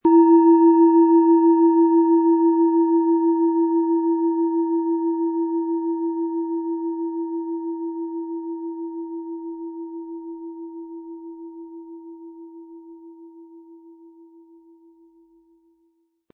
Von Hand getriebene Schale mit dem Planetenton Hopi-Herzton.
Im Audio-Player - Jetzt reinhören hören Sie genau den Original-Ton der angebotenen Schale.
Im Lieferumfang enthalten ist ein Schlegel, der die Schale harmonisch zum Klingen und Schwingen bringt.
PlanetentonHopi Herzton
MaterialBronze